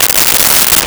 Plastic Zipper Long
Plastic Zipper Long.wav